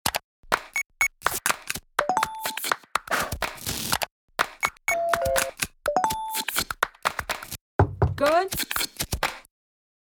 Sonic Branding